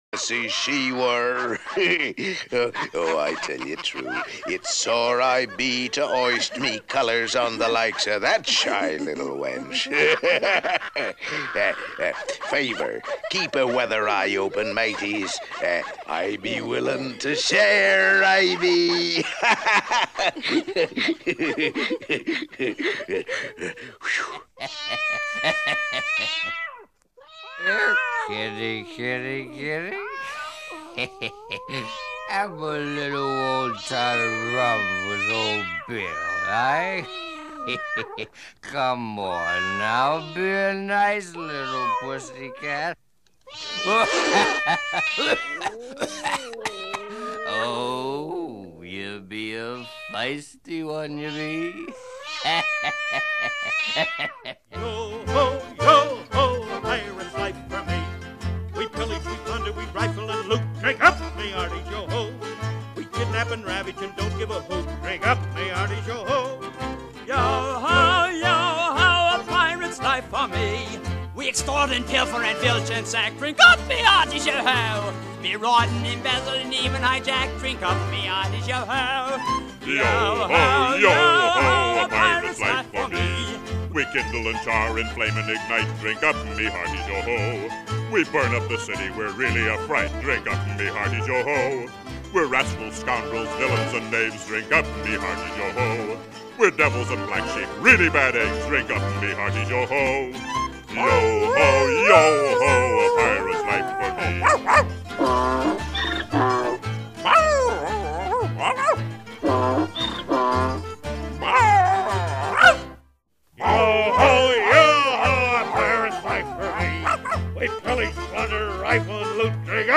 PRESS PLAY ALONG THE ARTICLE TO GET THE RIGHT MOOD*